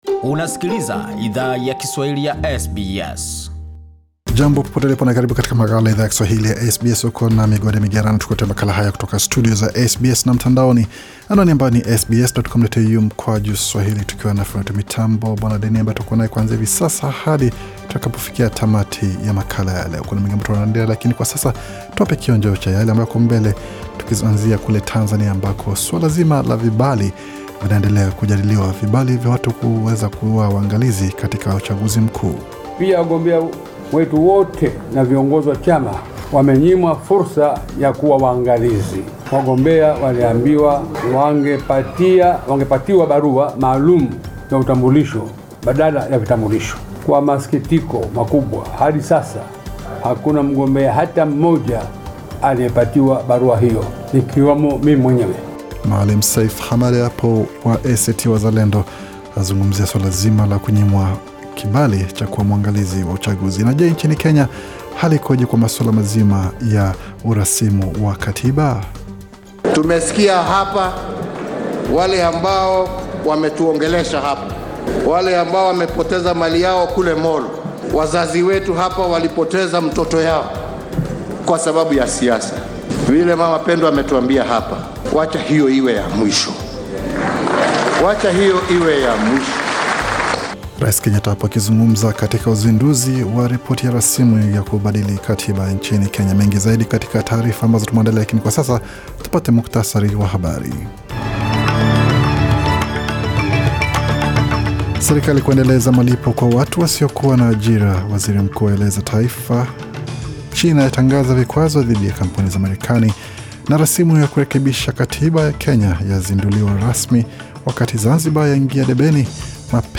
Taarifa ya habari 27 Oktoba 2020